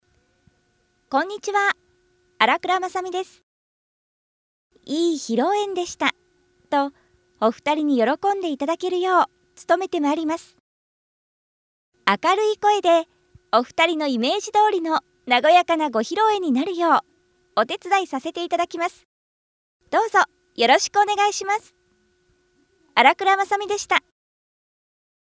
Voice
明るい声からしっとりとしたアナウンスまで内容や雰囲気に合わせた喋りが出来るパワフルな実力派！